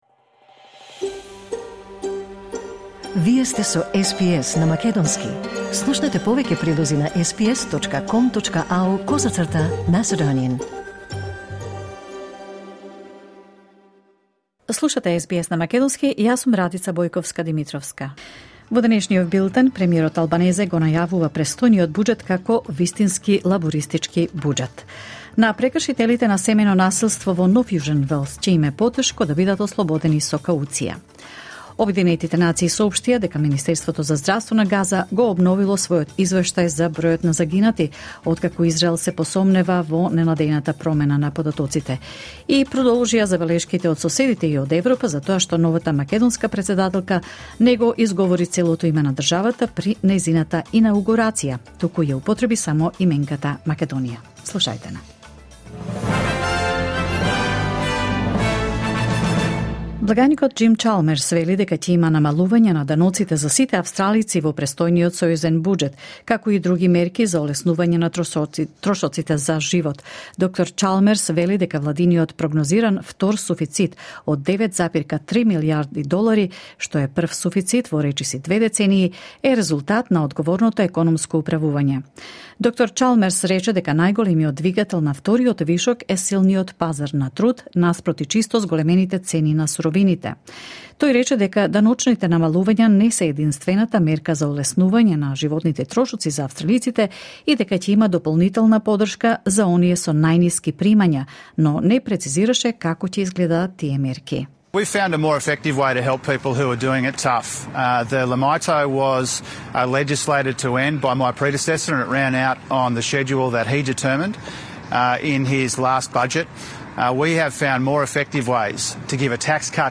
SBS News in Macedonian 14 May 2024